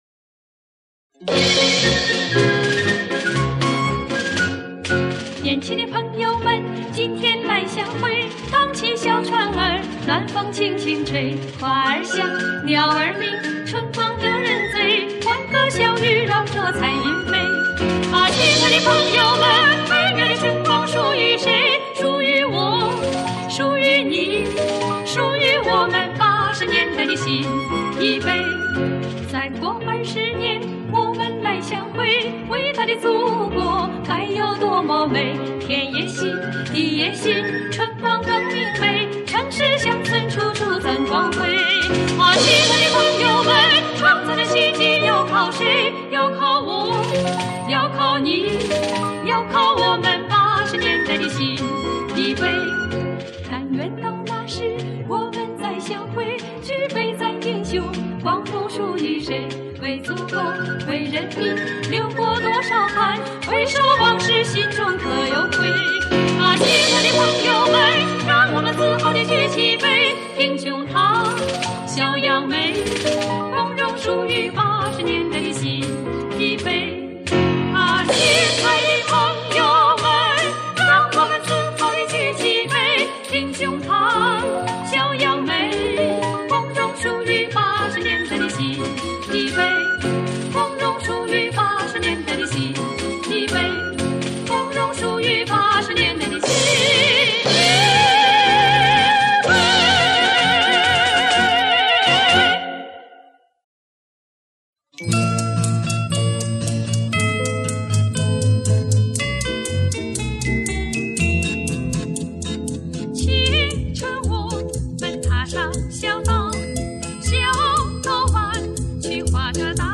她们的演唱声音和谐，音色甜美，行腔委婉，吐字清晰，尤其对歌曲的处理，细腻而富于感情。